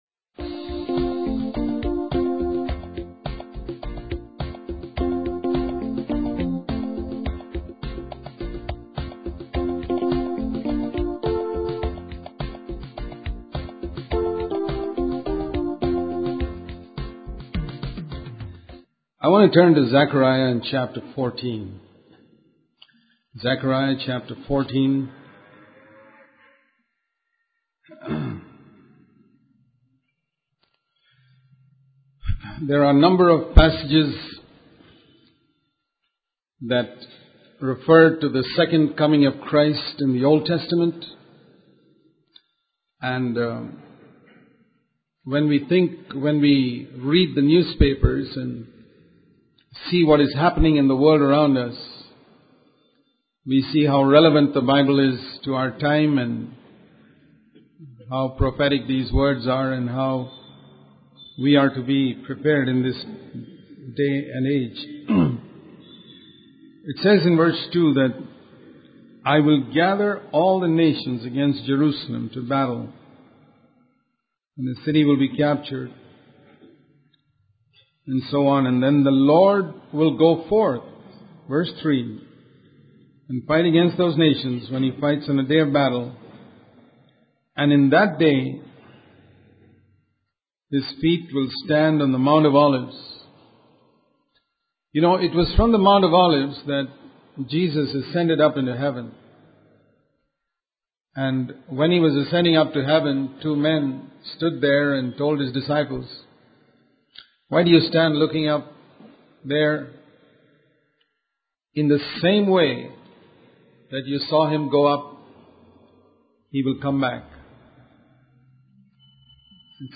In this sermon, the speaker emphasizes the importance of living a focused and spiritually alert life. He warns against allowing our hearts to be weighed down by worldly distractions such as excessive television and movies. The speaker encourages believers to be ready for the return of Jesus and to not let their hearts be intoxicated with worldly pleasures.